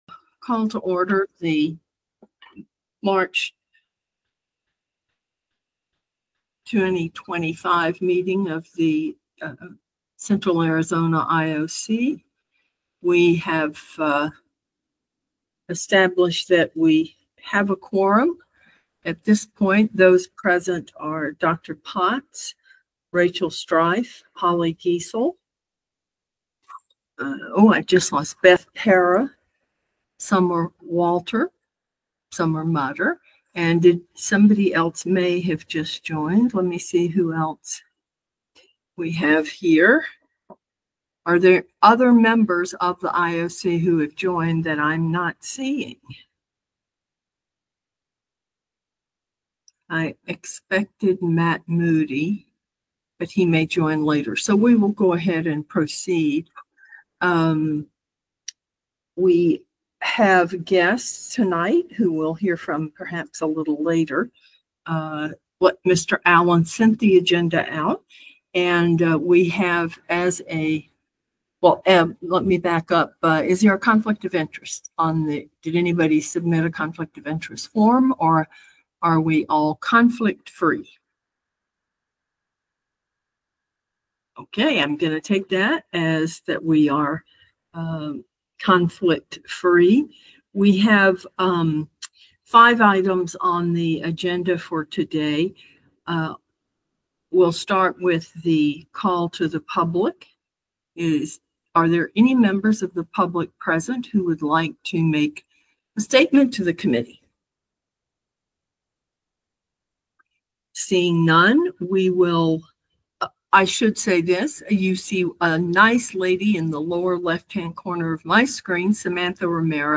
Virtual Meeting Only